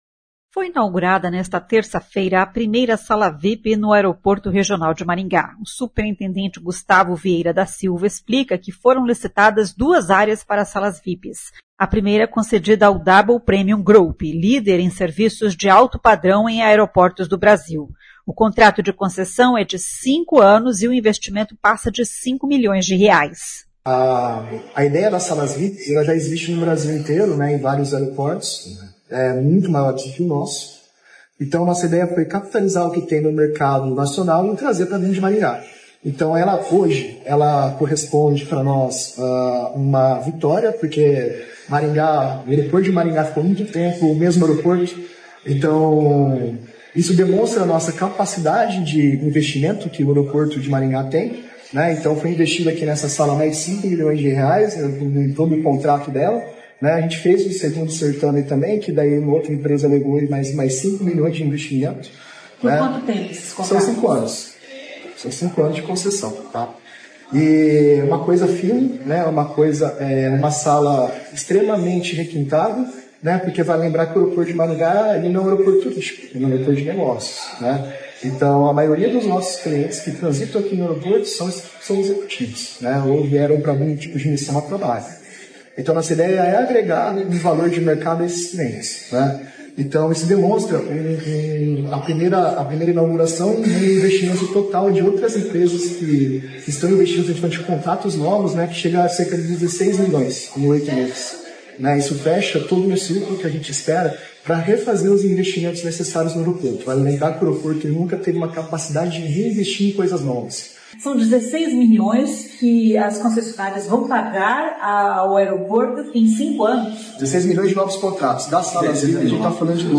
O prefeito Silvio Barros participou da cerimônia de inauguração da sala VIP e ressaltou a vitrine que o aeroporto representa para Maringá. [ouça o áudio]